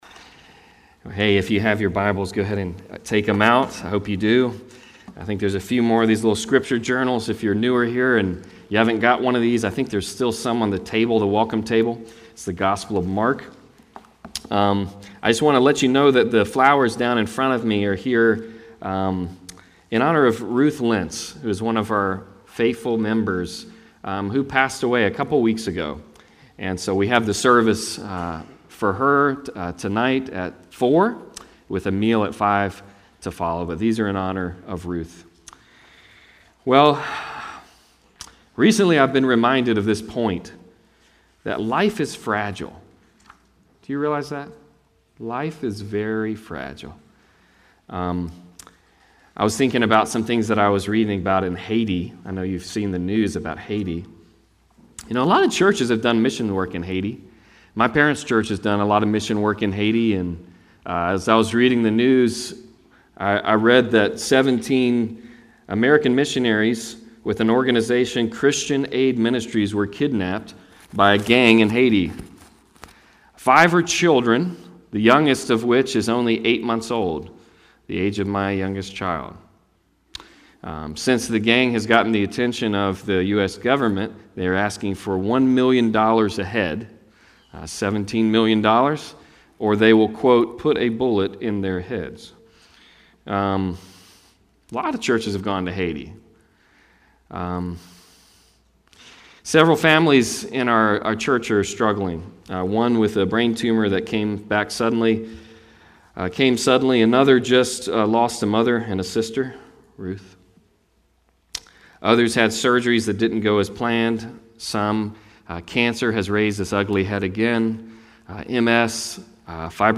Passage: Mark 6:30-43 Service Type: Sunday Service